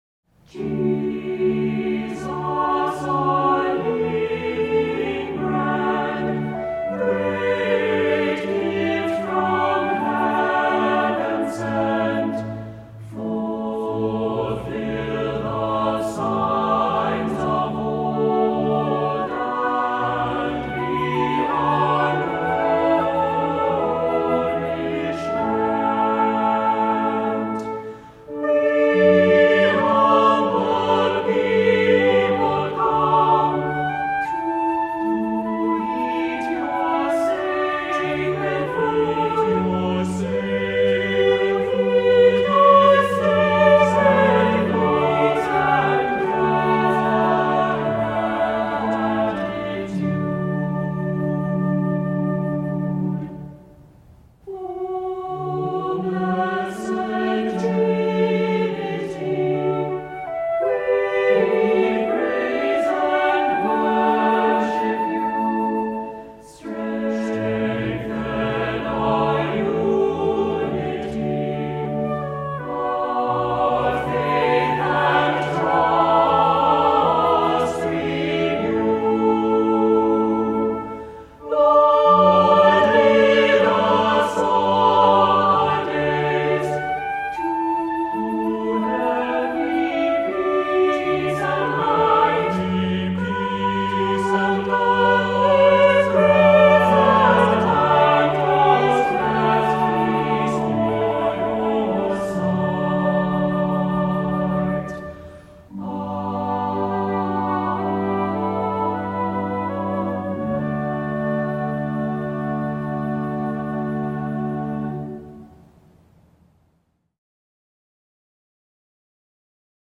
Voicing: "SATB, optional Assembly"